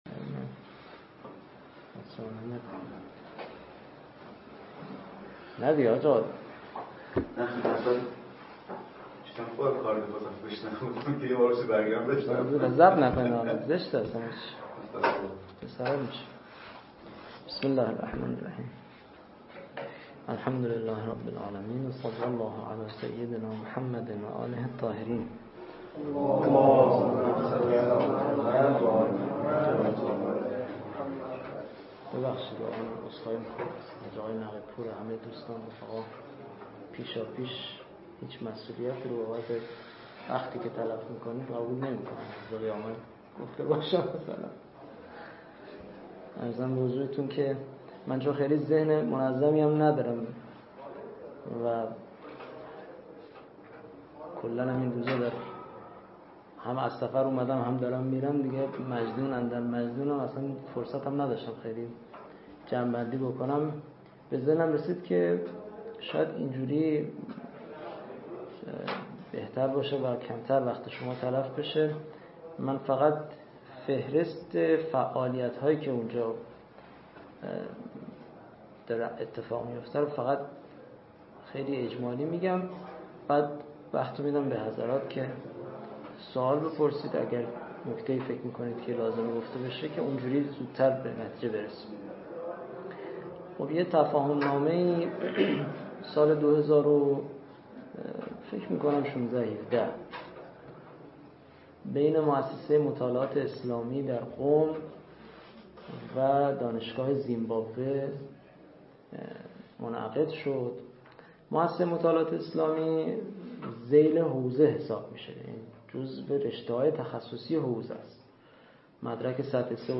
نشست علمی